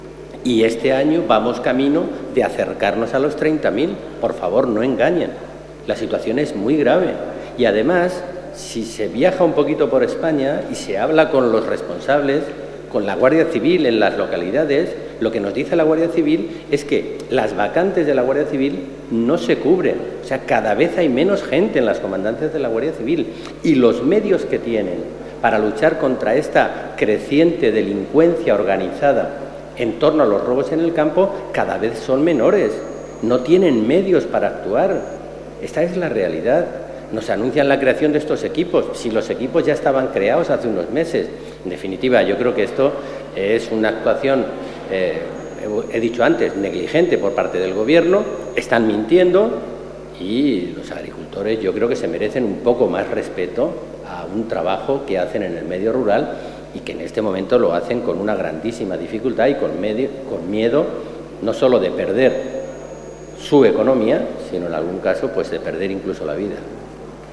Declaraciones de Alejandro Alonso Núñez en el Congreso sobre el aumento de los robos en las explotaciones agrícolas y ganaderas 26/09/2013